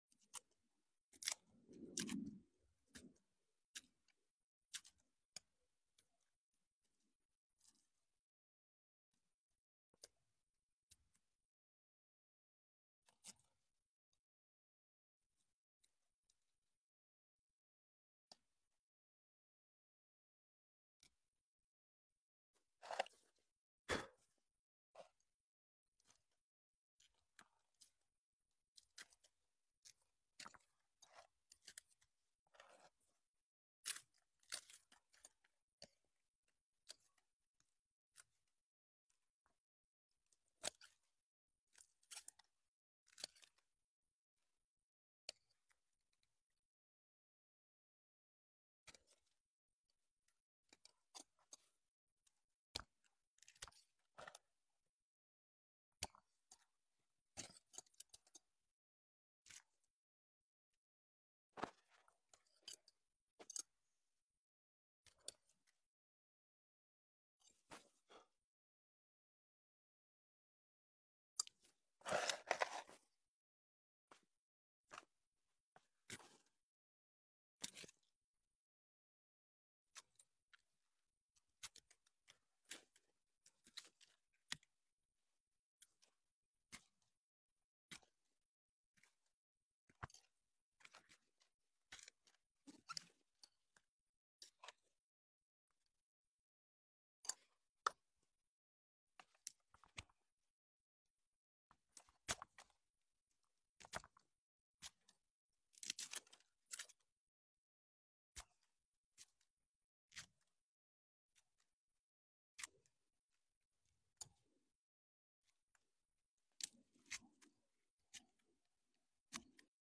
Settle in for an extended ASMR session as I peel away layers of dry succulent leaves. Enjoy every satisfying snap and crisp sound for pure relaxation and plant care bliss.